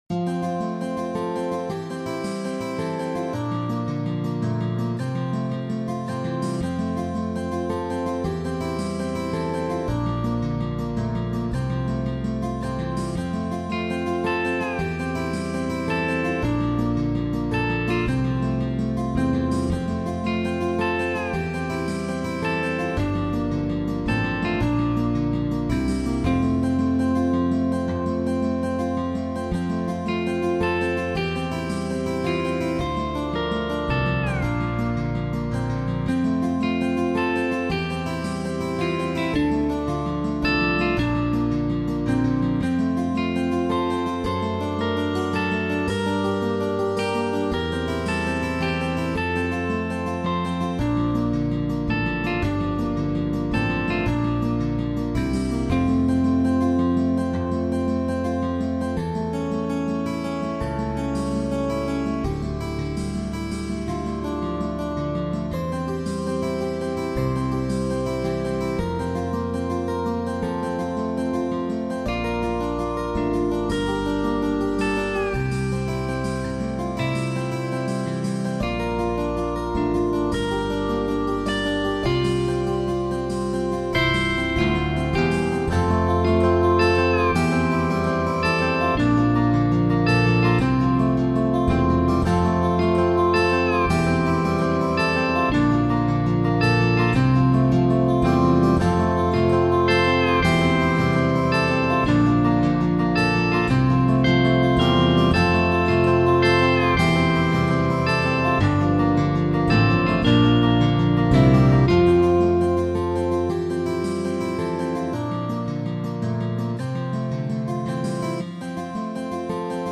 I wanted to do things differently so I made this sweet little guitar piece that’s acoustic, has a bass and an electric acoustic lead as well :)